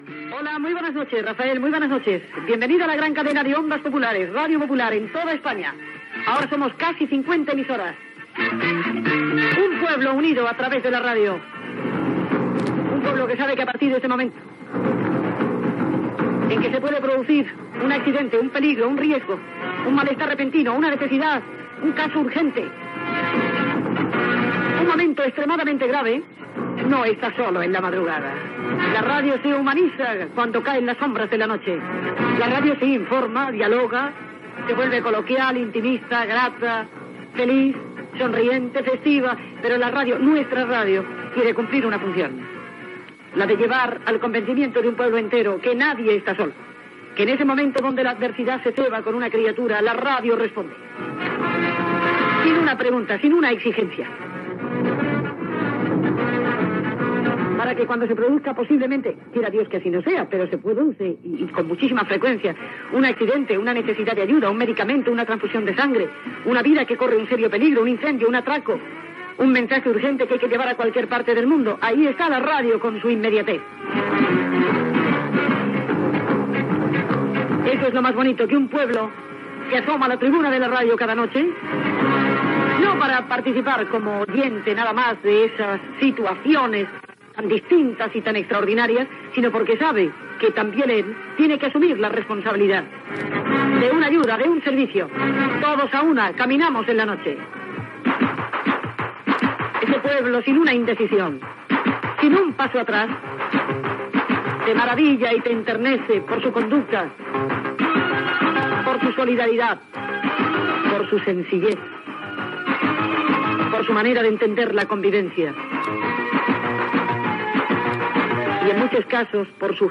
Primera edició del programa emesa per la Cadena COPE. Identificació de la Cadena i salutació inicial: la ràdio fa que no estiguis sol i està al servei del poble. Publicitat dels televisors Sanyo.